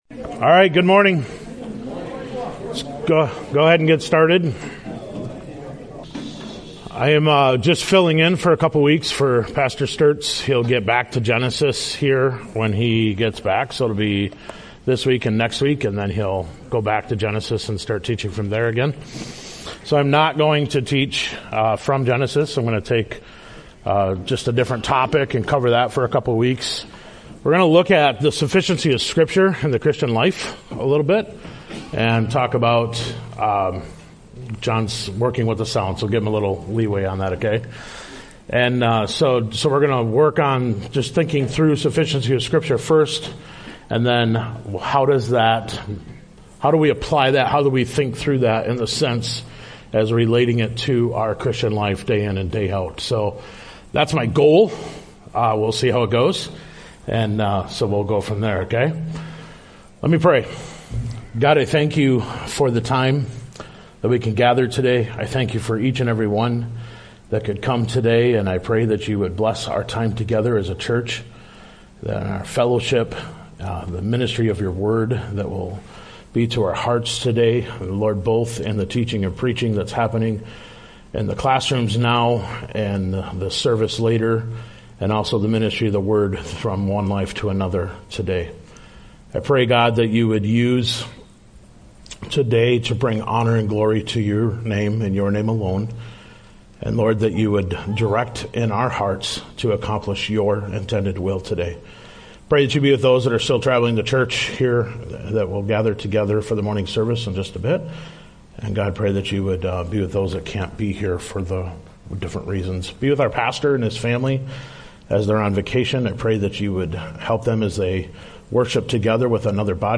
Sunday School
Sermon